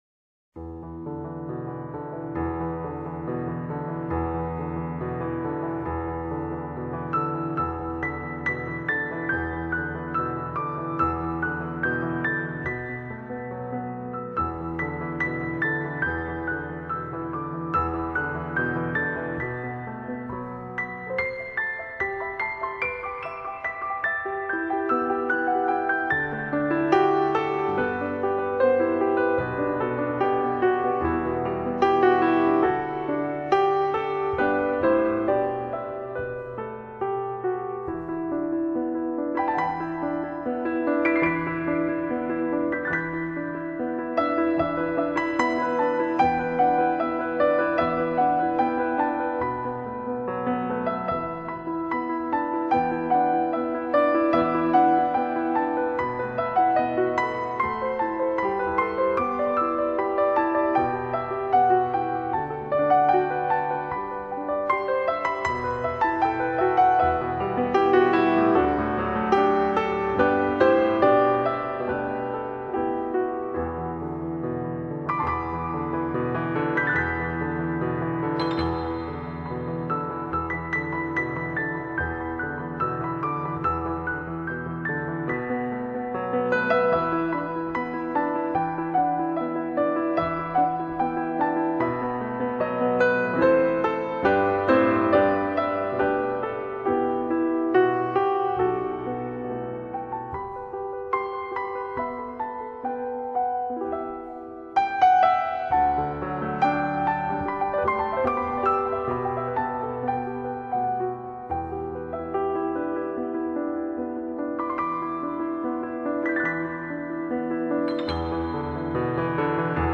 Piano, Holiday, Christmas, Easy Listening
Timeless holiday favorites featuring piano and strings